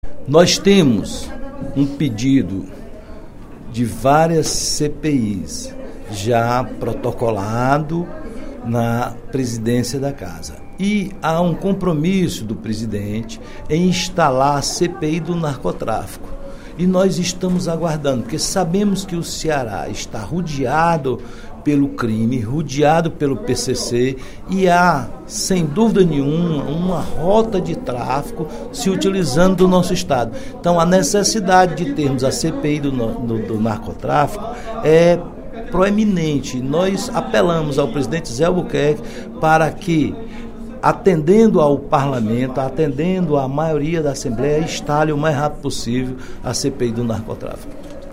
O deputado Roberto Mesquita (PSD) cobrou, durante o primeiro expediente da sessão plenária desta quinta-feira (19/05), a instalação da Comissão Parlamentar de Inquérito (CPI) do Narcotráfico.